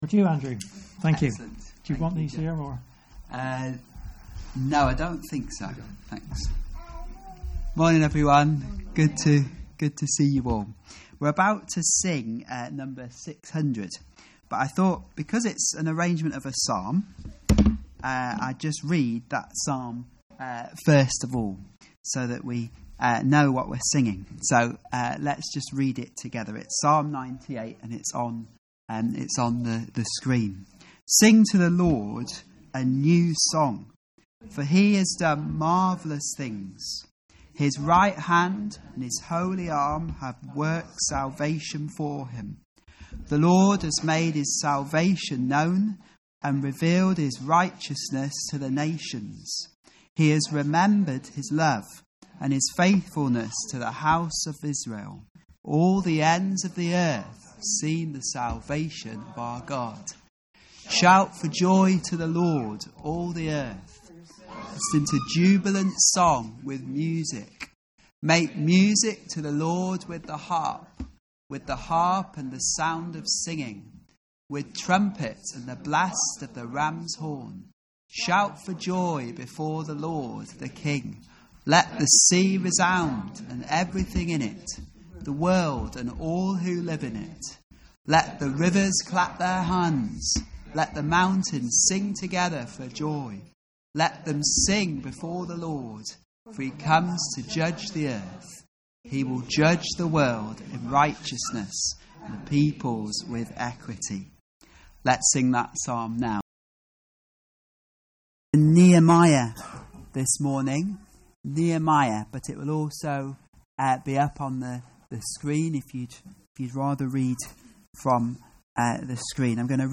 2025 Nehemiah’s Prayer Preacher